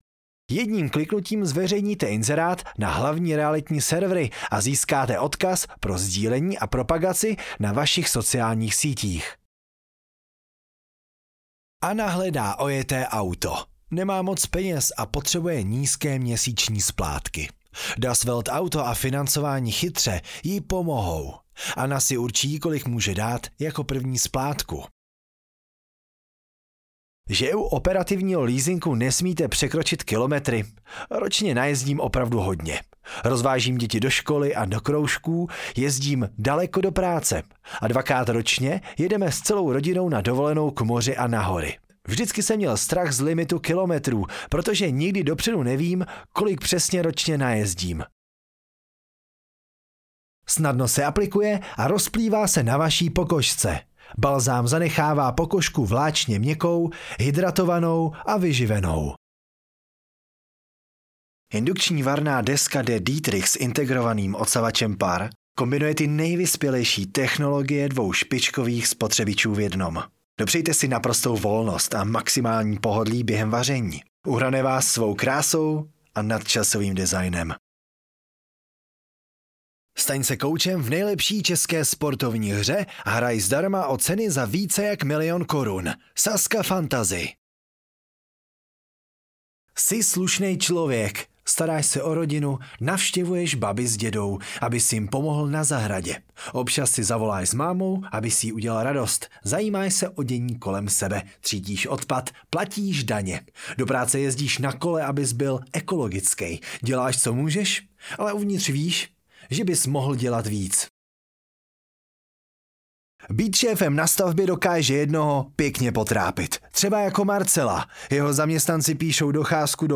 2min mužského hlasu pro produktová videa